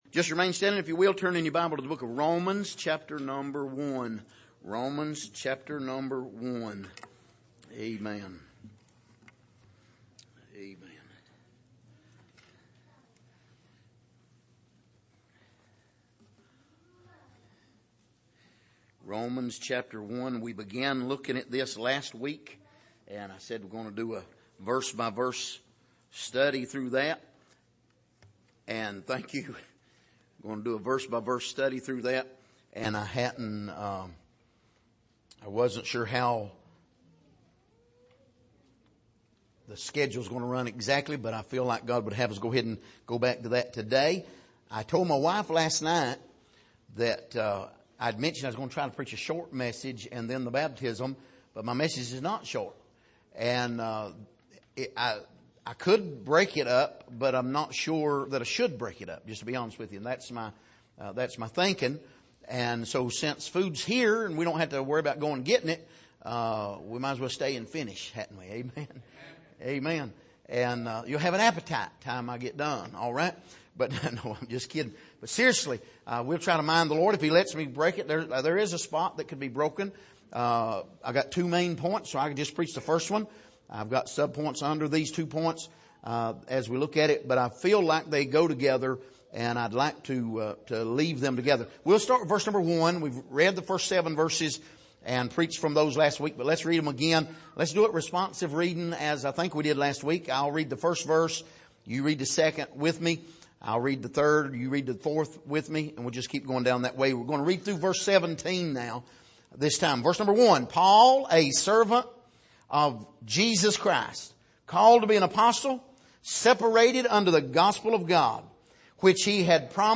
Passage: Romans 1:1-17 Service: Sunday Morning